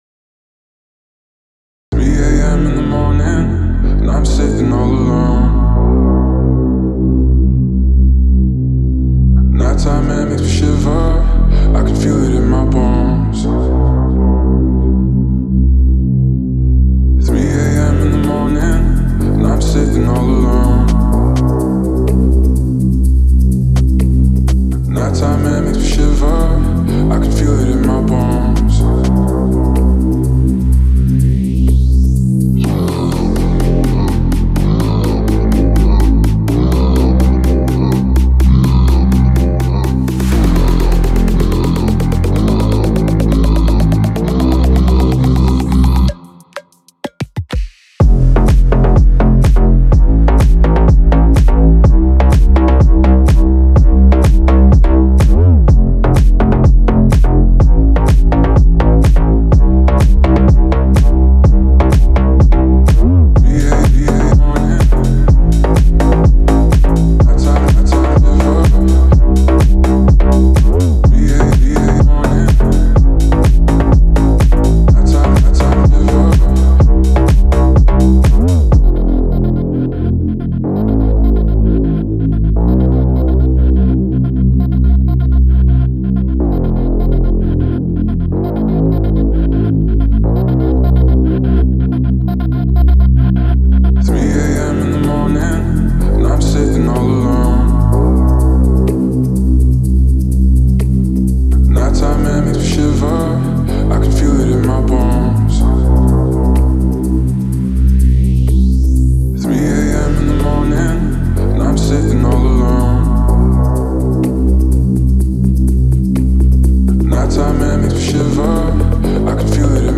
это эмоциональная поп-песня